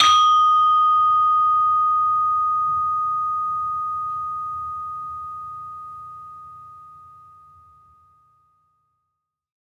HSS-Gamelan-1
Saron-4-D#5-f.wav